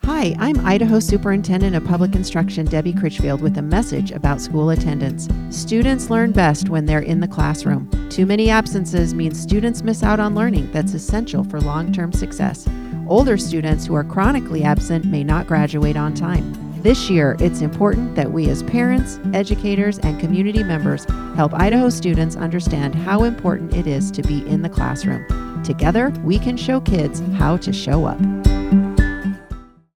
Radio Spot 2
Radio-Spot-2.mp3